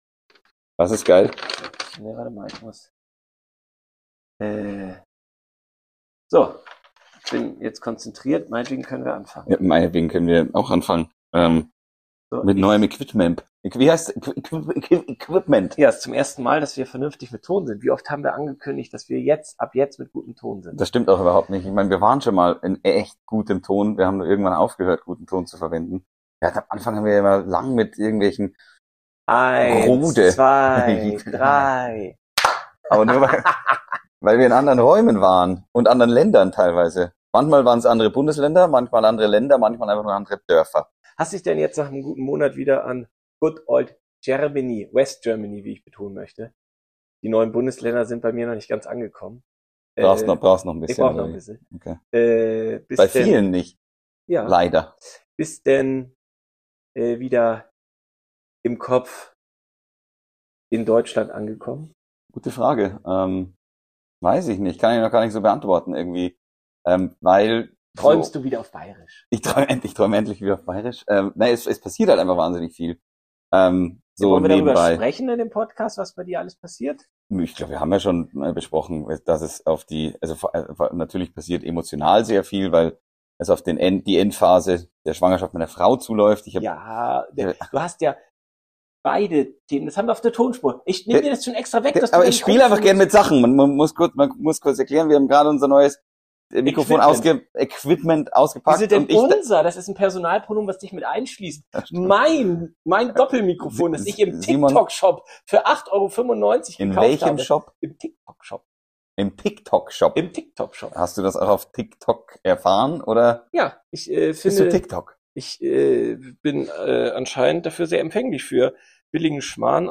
Von der Geburt bis zum Tod – alles kommt auf den Tisch. Ein echtes Gespräch, ungeschnitten, direkt, manchmal unangenehm nah dran.